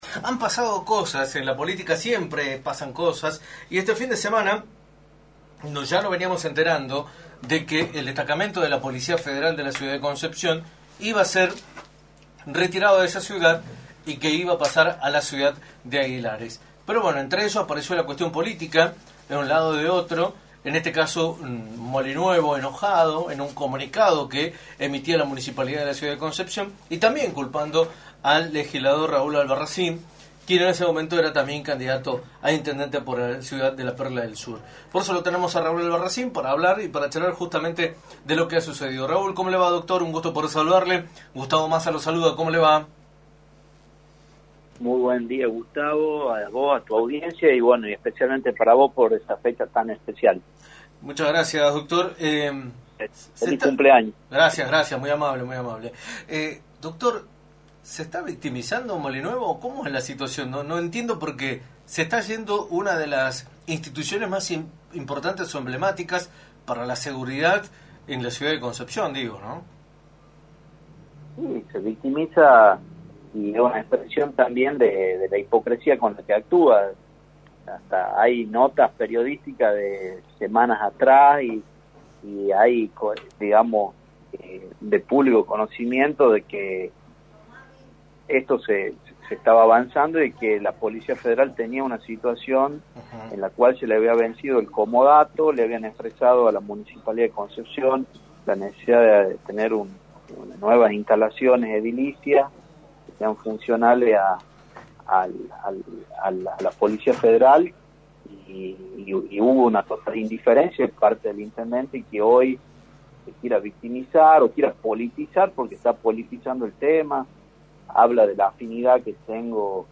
Raúl Albarracín, Legislador, respondió en Radio del Plata Tucumán, por la 93.9, a las críticas y acusaciones que recibió por parte del Intendente de Concepción, Alejandro Molinuevo, luego de la polémica por el traslado de la Federal de Concepción hacia la ciudad de Aguilares.